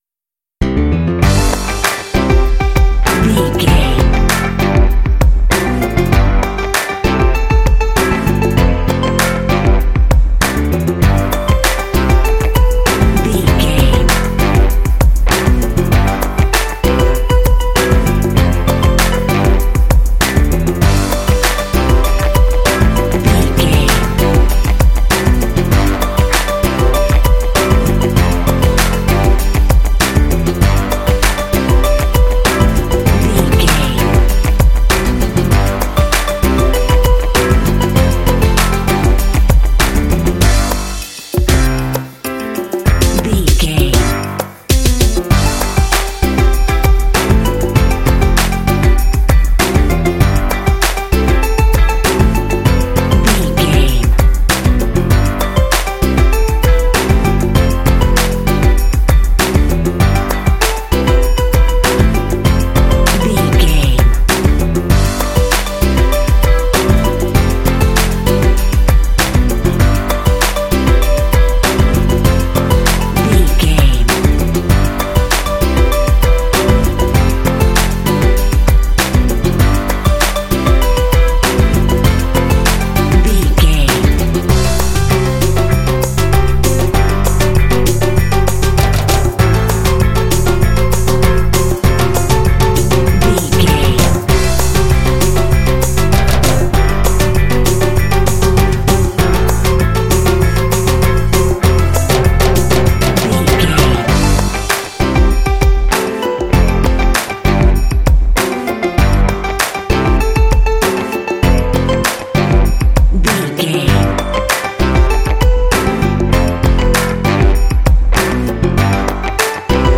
This cute hip hop track is great for kids and family games.
Uplifting
Ionian/Major
bright
happy
bouncy
piano
bass guitar
drums
electric guitar
synthesiser
strings
percussion
Funk